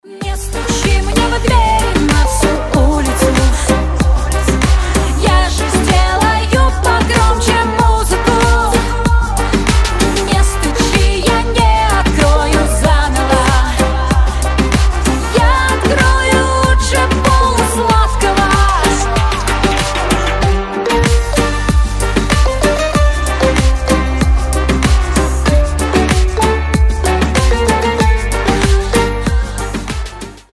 гитара
женский вокал
веселые